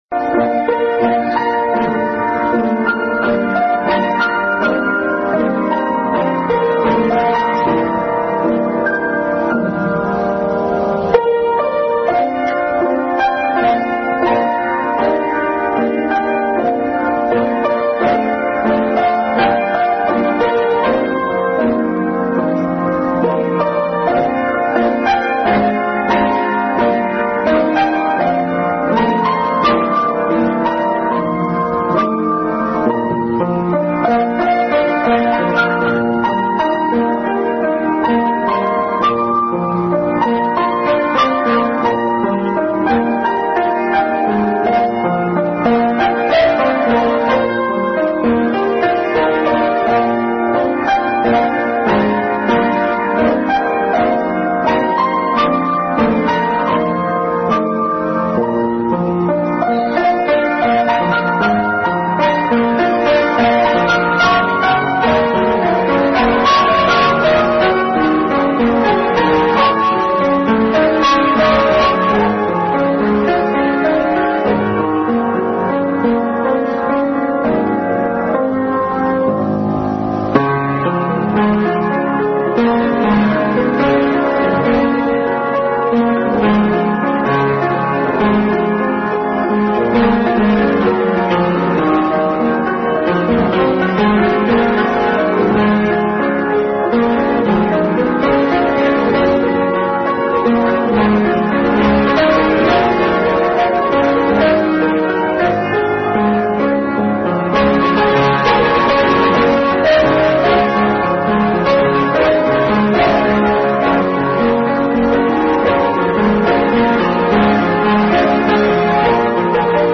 Bible Text: Matthew 23:37-39 | Mother’s Day 2019 Family Bible Hour Message.
Preceded by piano solo